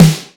ROCK SNARE.wav